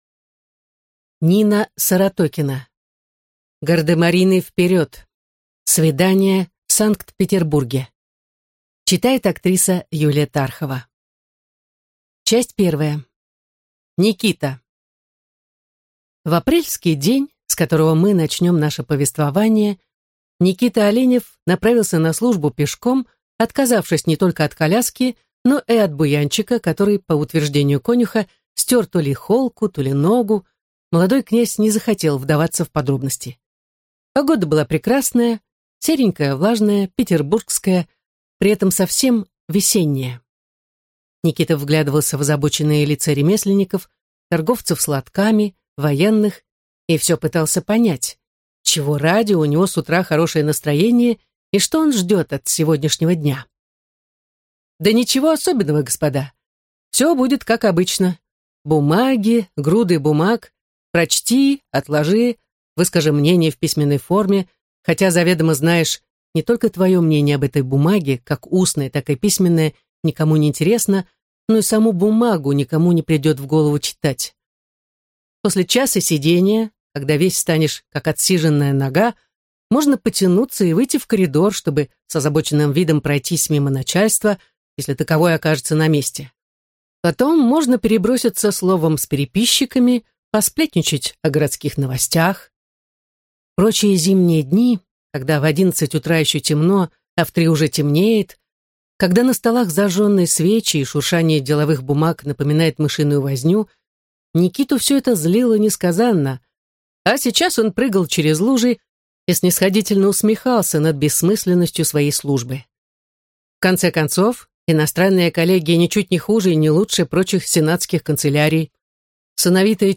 Аудиокнига Гардемарины, вперед! Свидание в Санкт-Петербурге | Библиотека аудиокниг